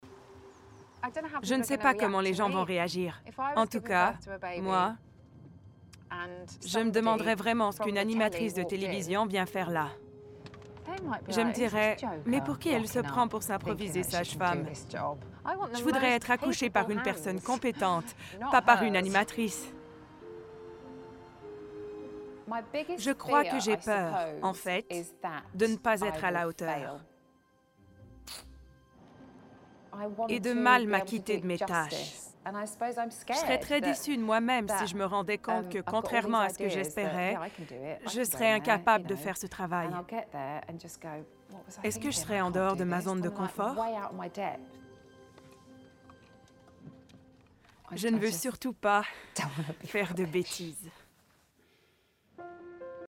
DÉMO VOIX
Comédienne